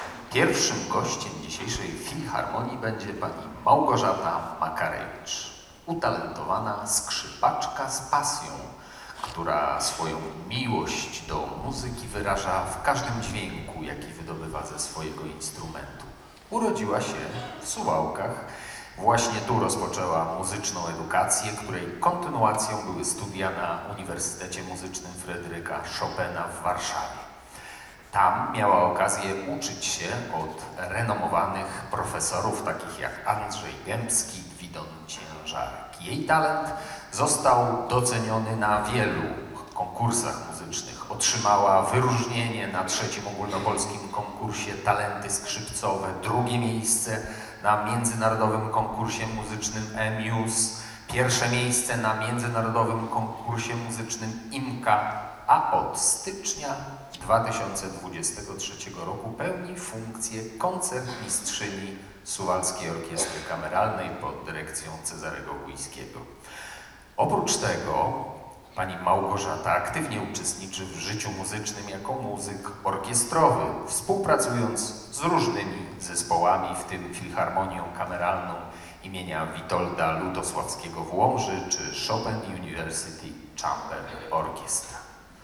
„Jesienne barwy muzyki” – nastrojowy piątkowy koncert w SOK-u